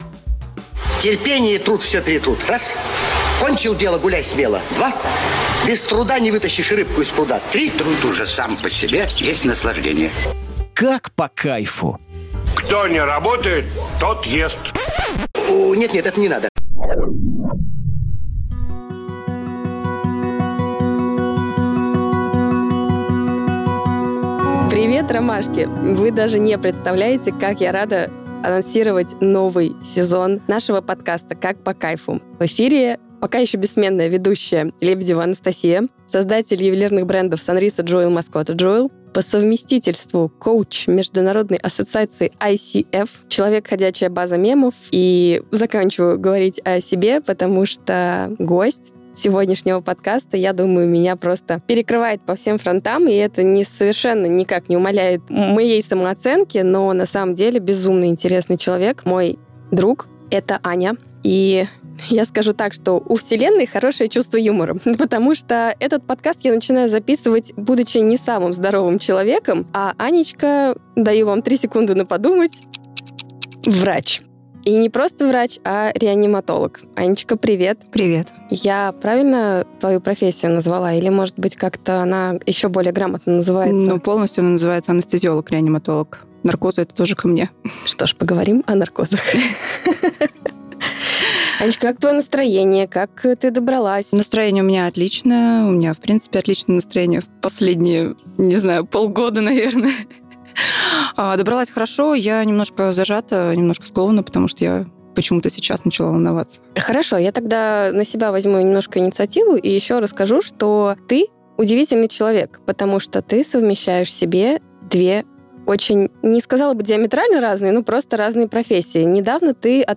Слушайте внимательно, в выпуск вмонтировали кусочек, озвученный тифлокомментатором.